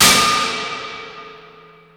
DEJECTION SNARE - HIT.wav